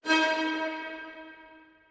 Stab